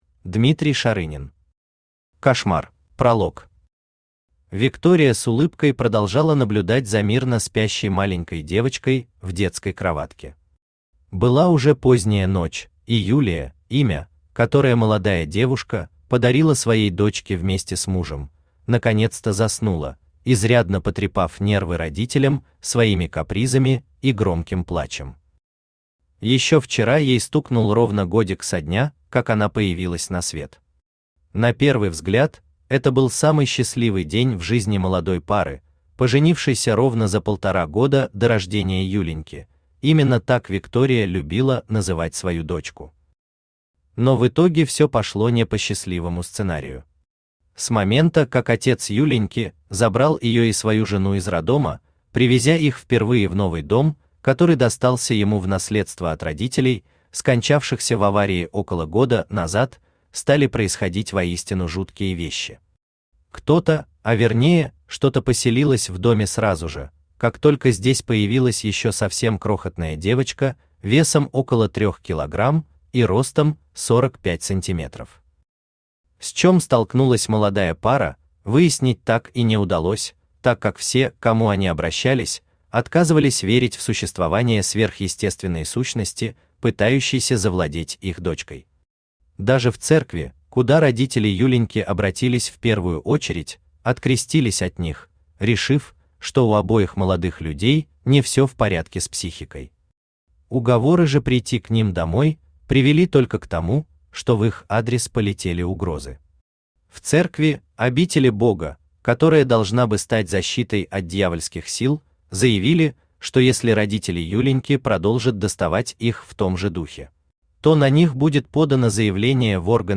Аудиокнига Кошмар | Библиотека аудиокниг
Aудиокнига Кошмар Автор Дмитрий Андреевич Шарынин Читает аудиокнигу Авточтец ЛитРес.